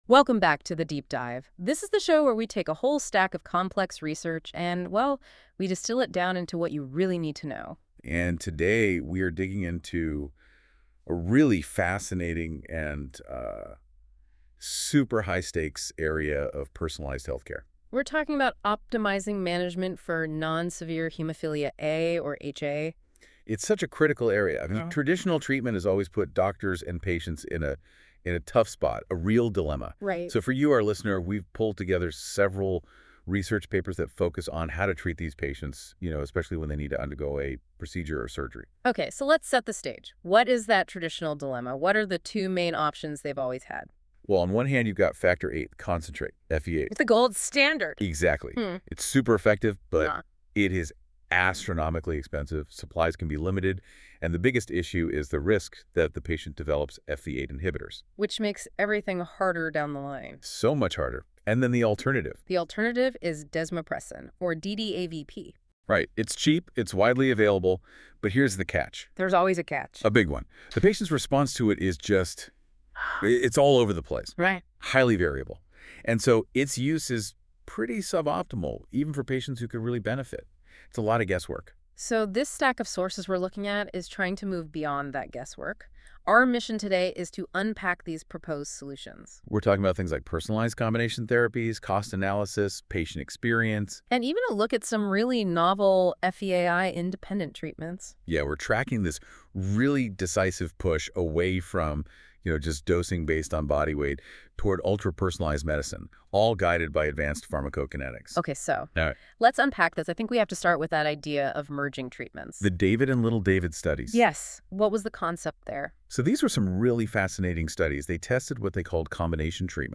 With AI a podcast is generated automatically from the thesis.